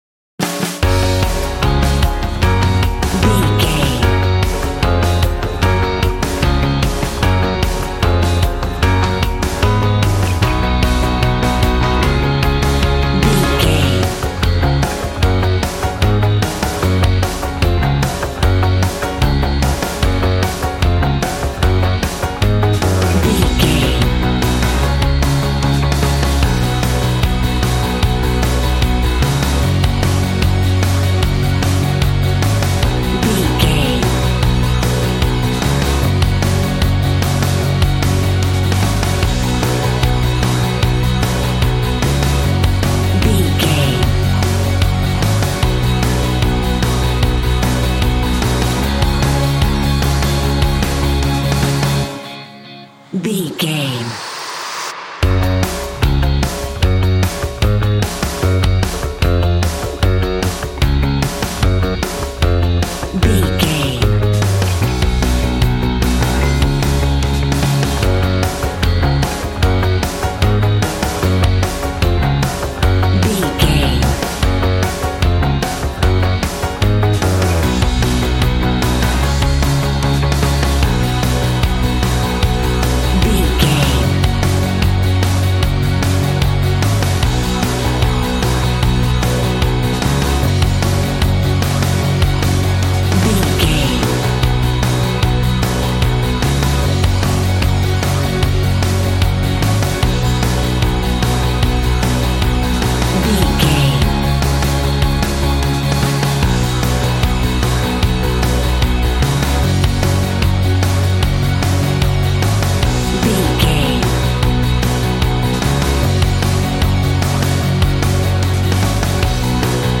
Ionian/Major
G♭
groovy
happy
electric guitar
bass guitar
drums
piano
organ